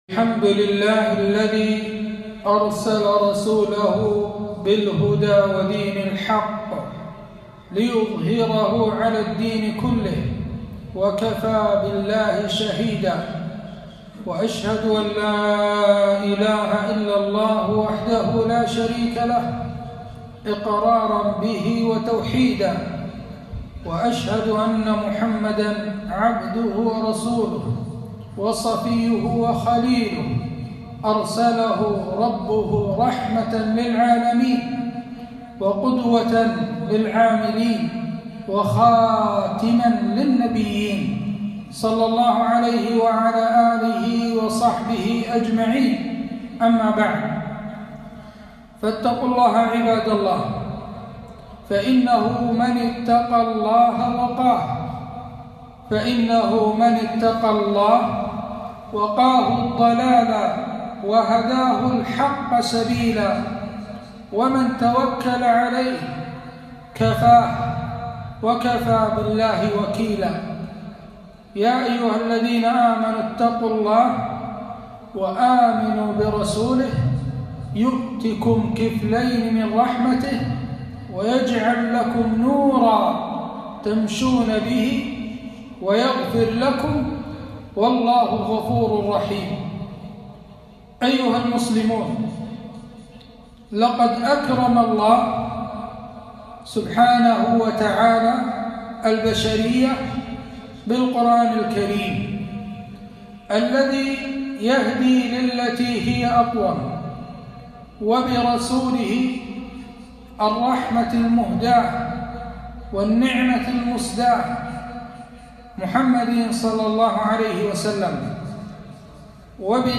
خطبة - عظمة الإسلام وواجب المسلمين